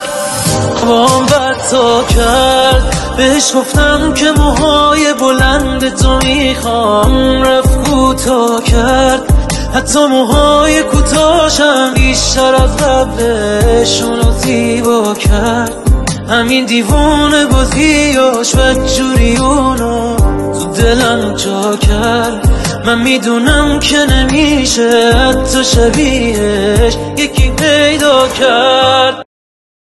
موسیقی پاپ و ترانه‌های عاشقانه